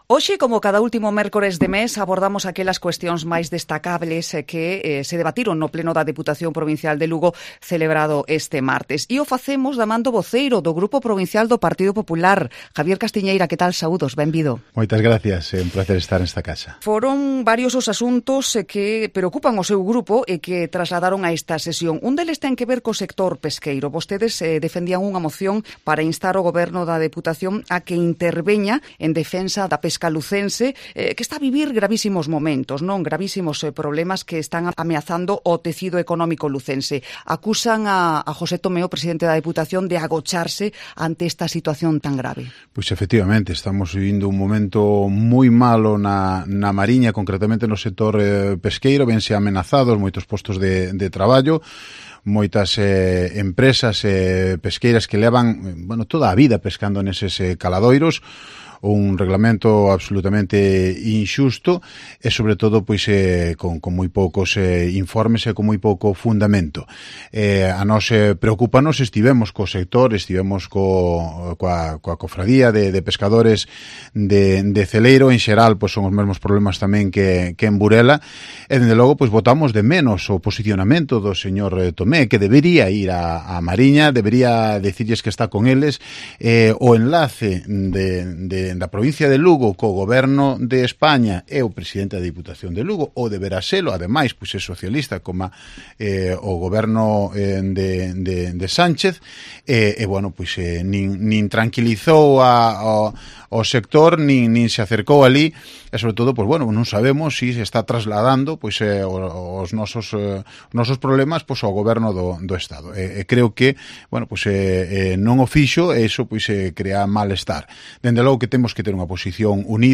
AUDIO: El portavoz del grupo provincial del Partido Popular repasó en los estudios de COPE Lugo los principales temas tratados en el pleno de...